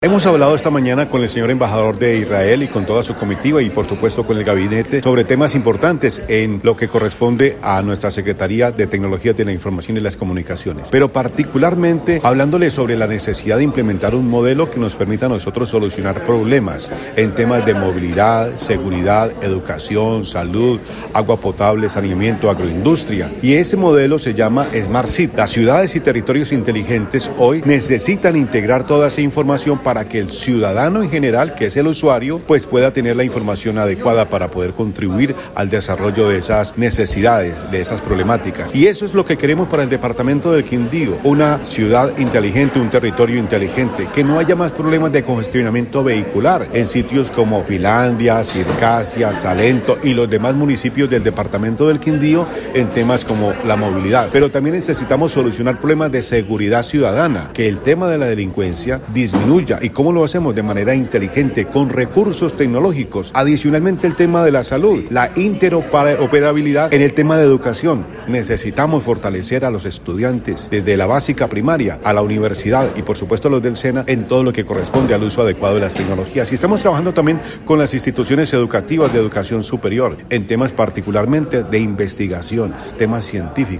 Audio de: Héctor Fabio Hincapié Loaiza, secretario TIC del departamento